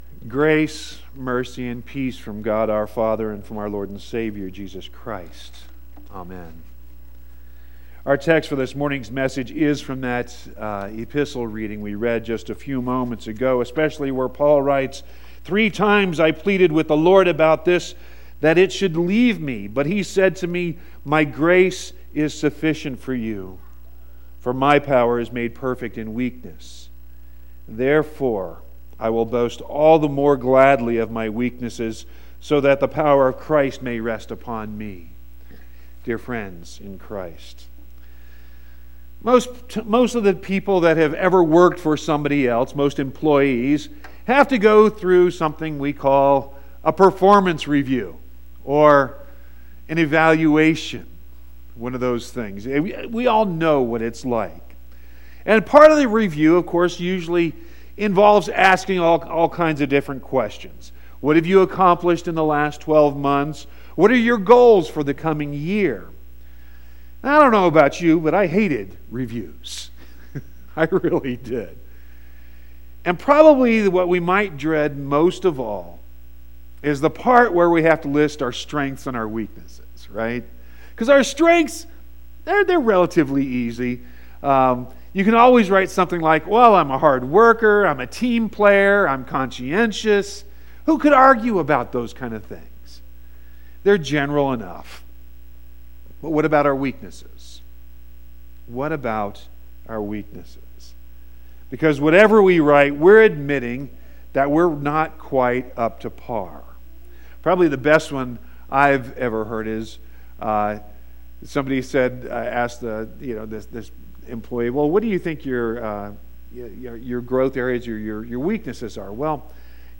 7-08-18-sermon.mp3